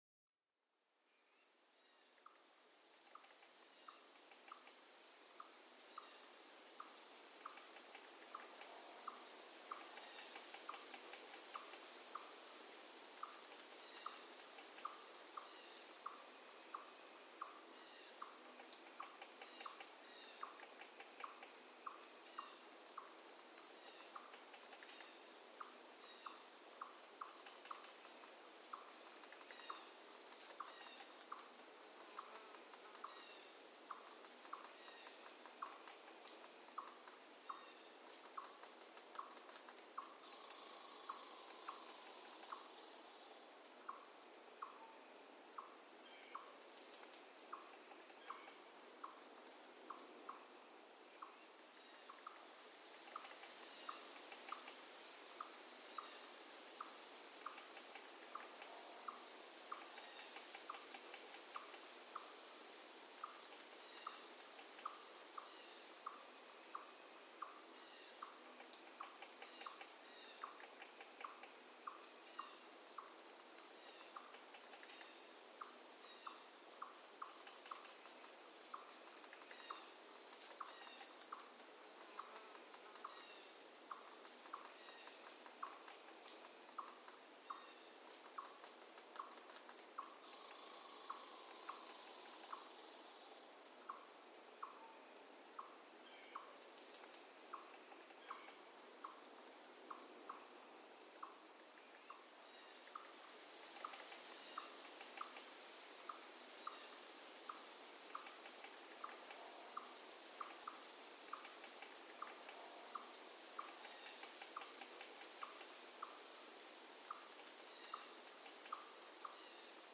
Descubre la sinfonía natural de los pájaros del bosque, un verdadero concierto al aire libre. Cada trino y gorjeo te transporta al corazón de un edén verde, donde el estrés se desvanece con cada nota.